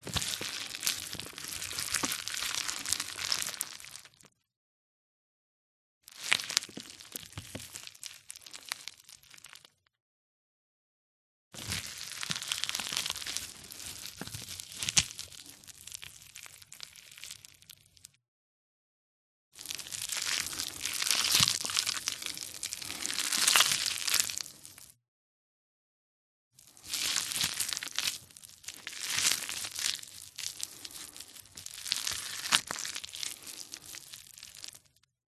• Качество: высокое
Звуки спагетти: как шумят готовые макароны при смешивании с соусом